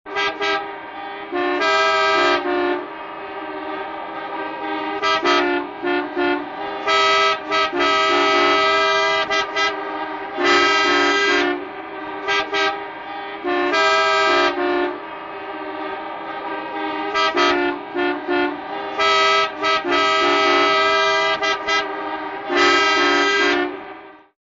На этой странице собраны звуки автомобильных пробок — гудки машин, шум двигателей, переговоры водителей.
Хороший вариант дорожного затора для монтажа